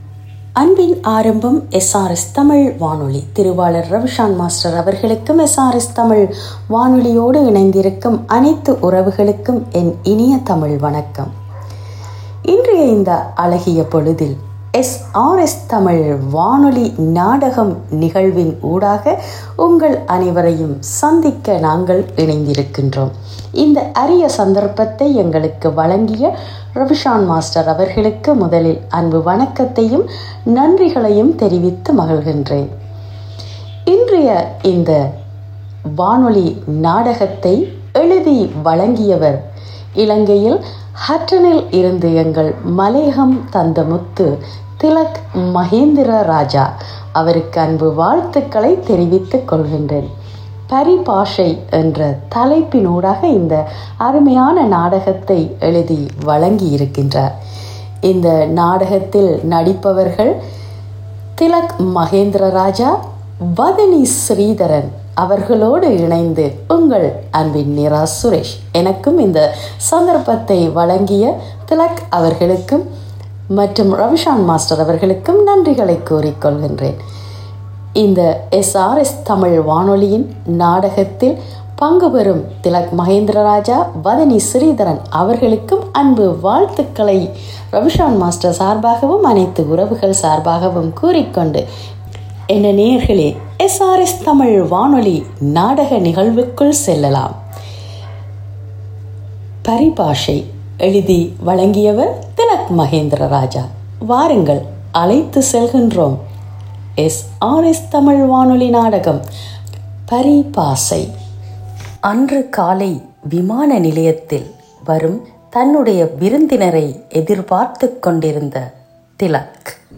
வானொலி நாடகம் 07.07.25